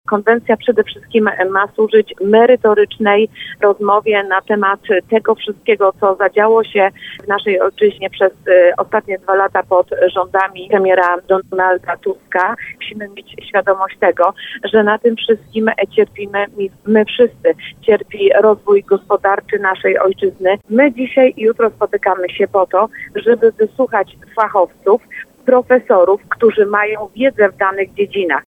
Wśród nich jest poseł PiS Anna Pieczarka, która była gościem porannej rozmowy Słowo za Słowo.